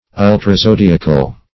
Search Result for " ultrazodiacal" : The Collaborative International Dictionary of English v.0.48: Ultrazodiacal \Ul`tra*zo*di"a*cal\, a. [Pref. ultra- + zodiacal.]
ultrazodiacal.mp3